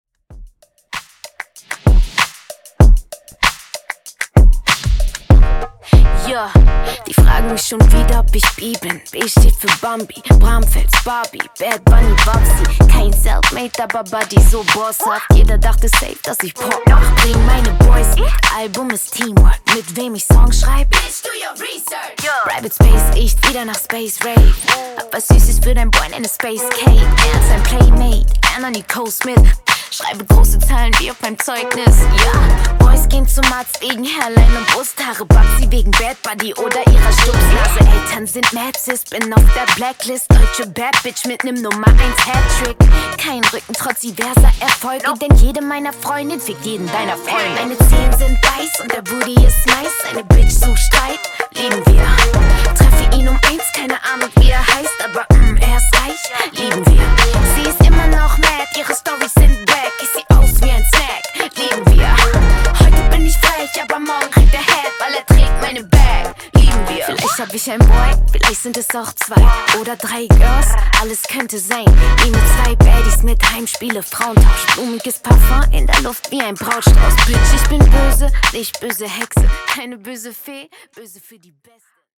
Genres: R & B , RE-DRUM
Clean BPM: 74 Time